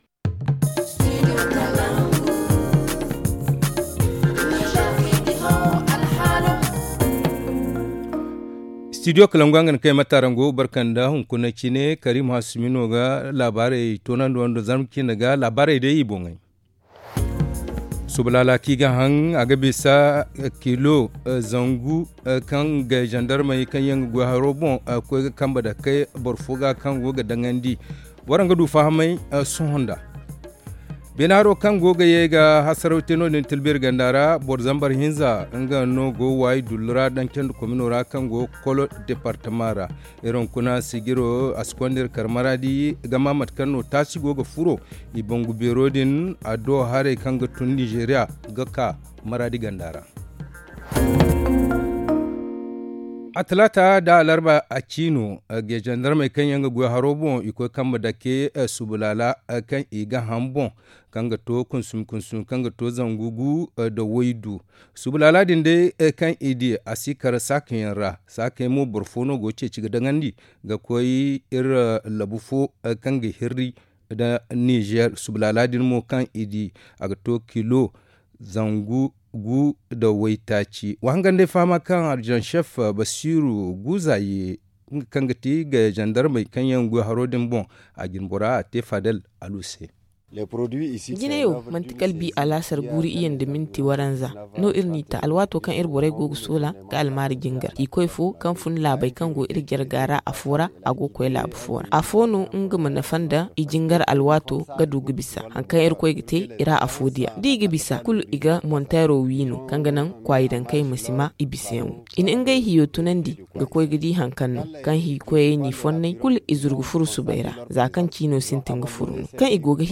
Le journal du 18 août 2022 - Studio Kalangou - Au rythme du Niger